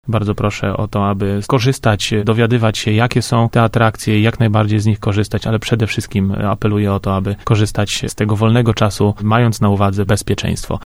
Do tego namawia prezydent Tarnobrzega Łukasz Nowak.